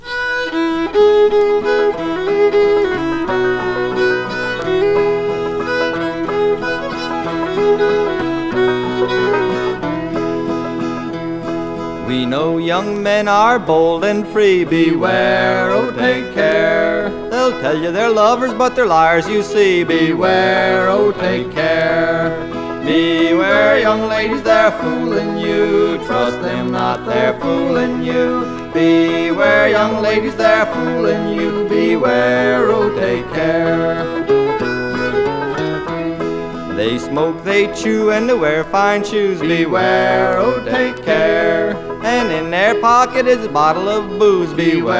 Folk, Bluegrass　USA　12inchレコード　33rpm　Mono